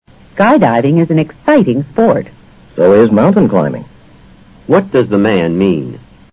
托福听力小对话【93】Skydiving
男方这样说是补充了女方的说话内容。